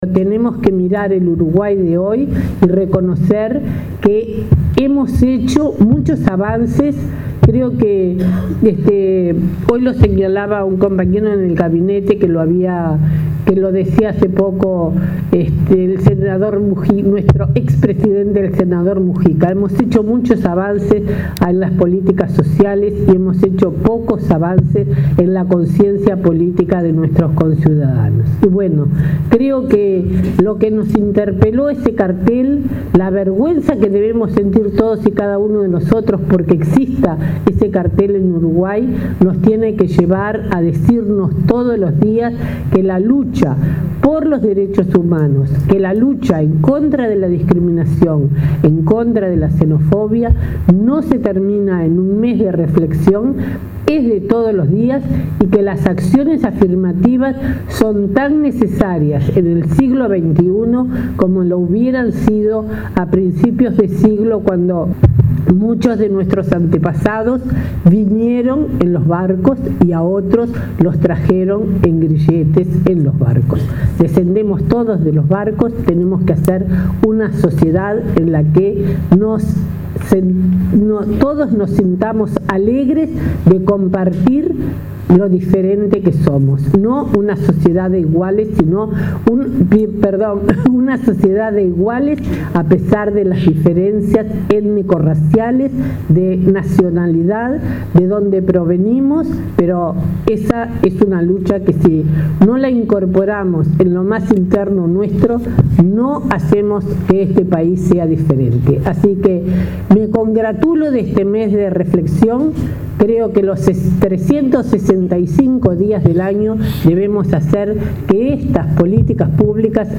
“La lucha por los derechos humanos y contra la discriminación es de todos los días, no se termina en un mes de reflexión. Si no la incorporamos en lo más interno nuestro, no hacemos que este país sea diferente”, aseguró la ministra de Educación, María Julia Muñoz, en el acto por el Mes de la Afrodescendencia. Reconoció que se ha avanzado en las políticas sociales, pero resta trabajar para cambiar la conciencia de la sociedad.